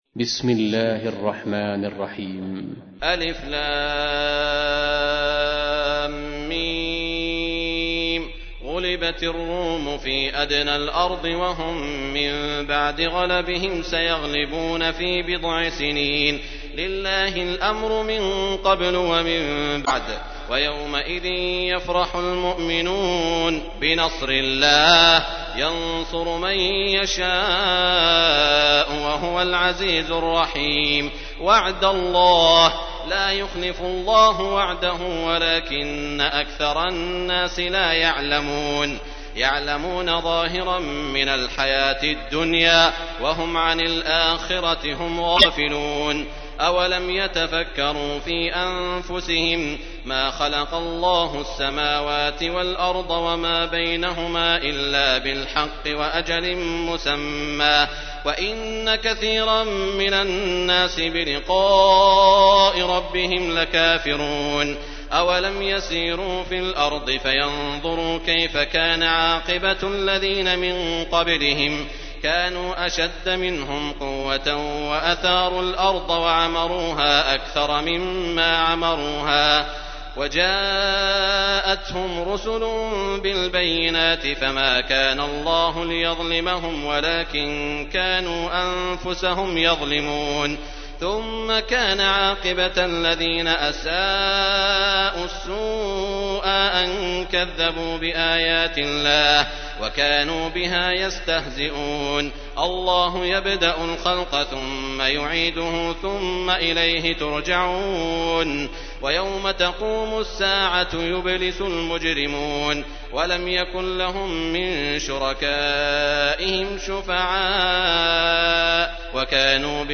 تحميل : 30. سورة الروم / القارئ سعود الشريم / القرآن الكريم / موقع يا حسين